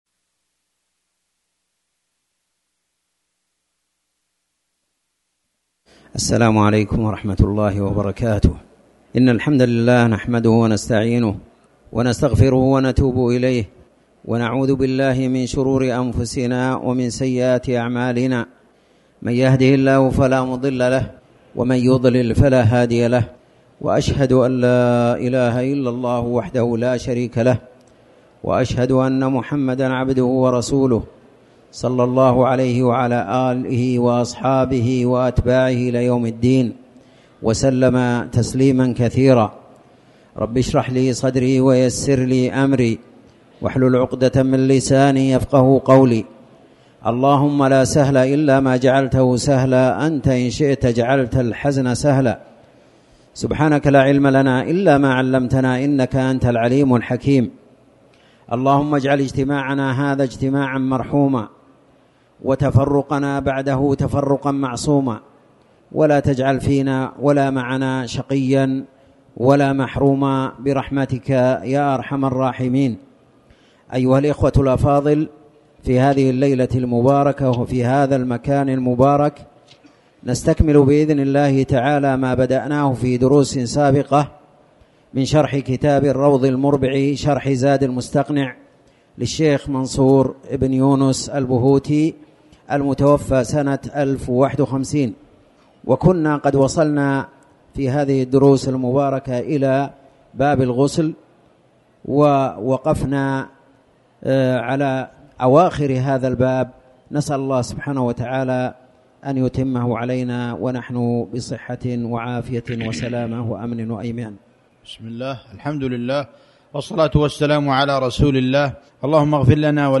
تاريخ النشر ٣ ربيع الثاني ١٤٤٠ هـ المكان: المسجد الحرام الشيخ